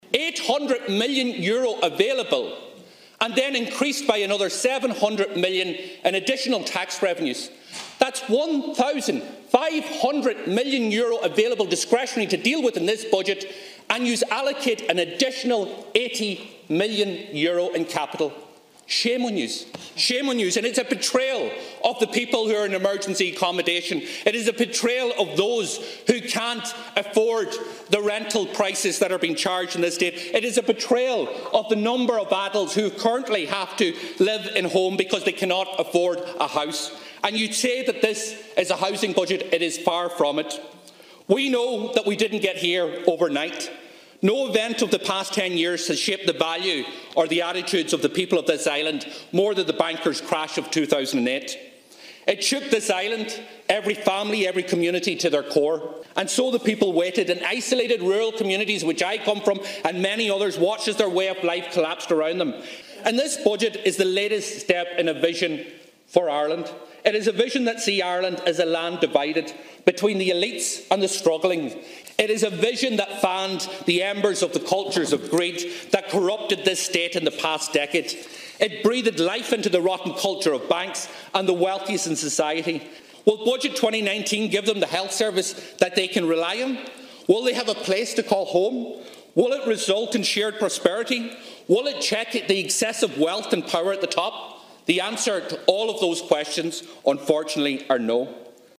Speaking in the Dail this afternoon, Deputy Doherty said the budget is a shameful attempt to tackle the homeless crisis and has failed to address the issues at the core of society, such as; the overcrowding crisis in the country’s hospitals and the infrastructure deficit in rural Ireland.